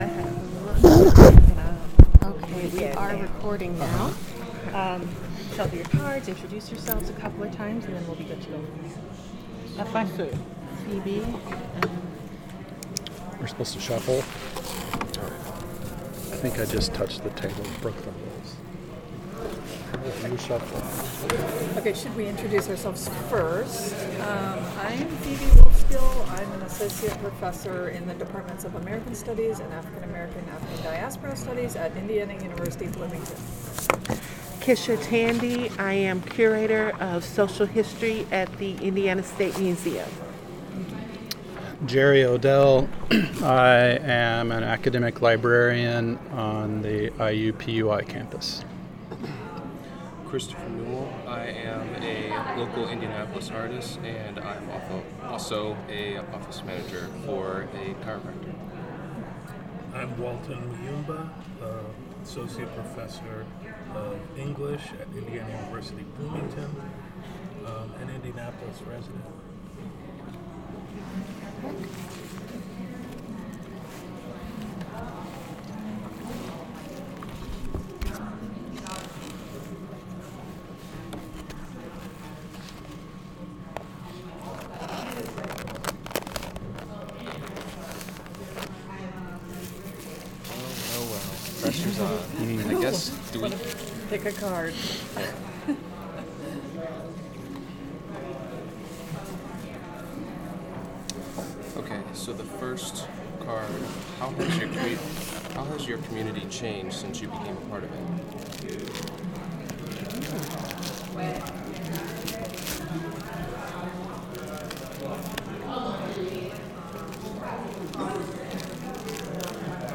Type sound recording-nonmusical
Genre oral history